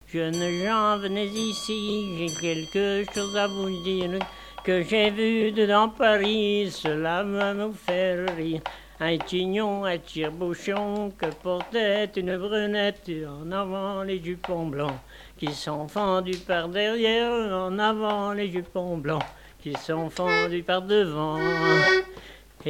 Chants brefs - A danser
danse : polka
Pièce musicale inédite